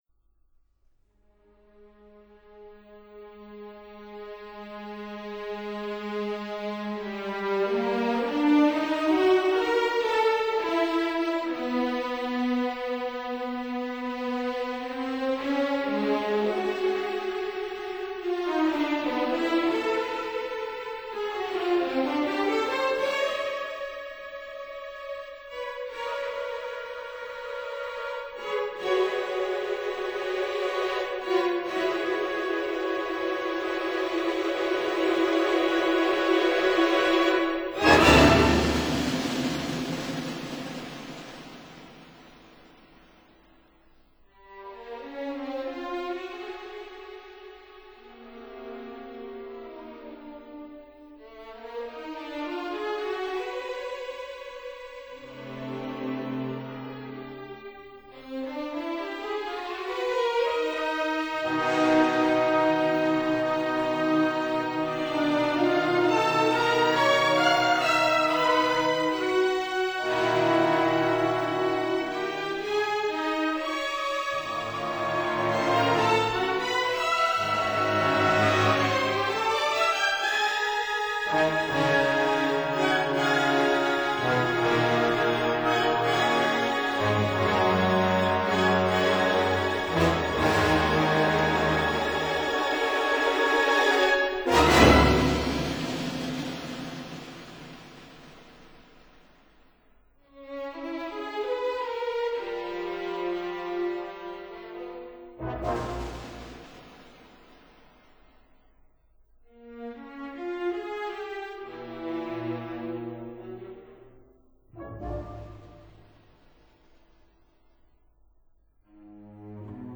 Symphony